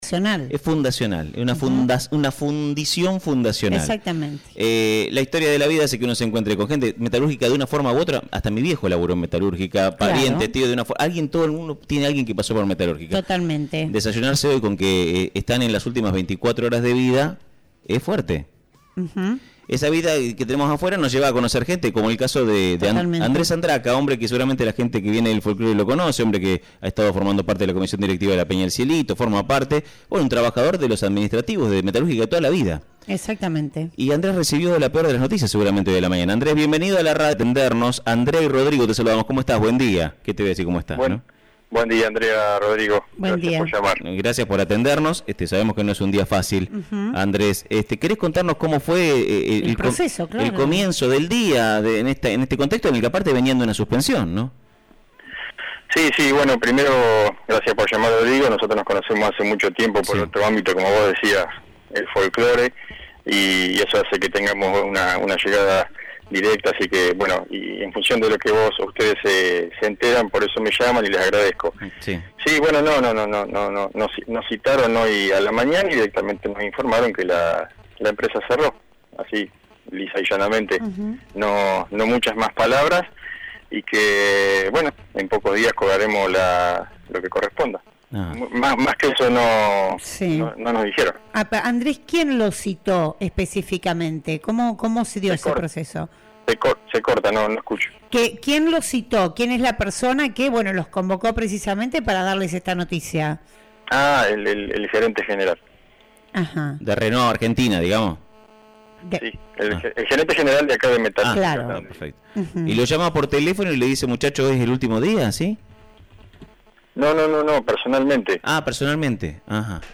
señaló en diálogo con “Dulce o Amargo” por la 104.1 Tandil FM